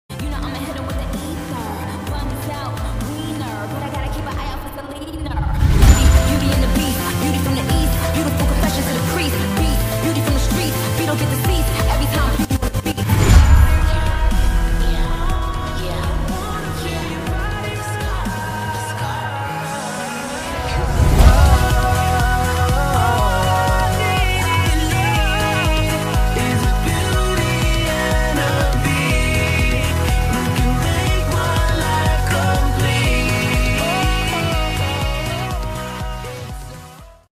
#8dmusic